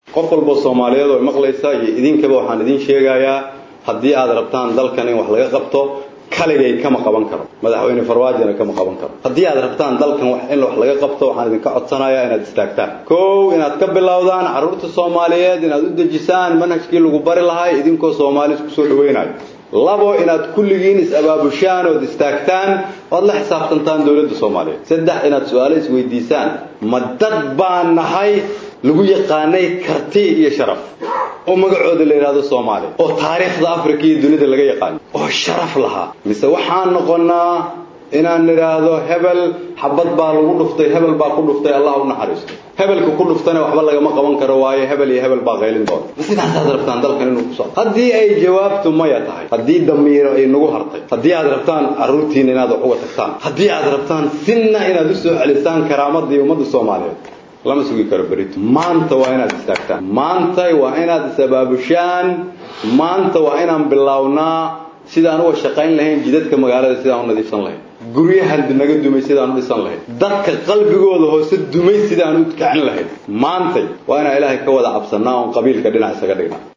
Xasan Cali Khayre oo ka hadlaayay kulankii shirweynaha Qaran ahaa ee looga hadlaayay Manhajka waxbarashada Dalka.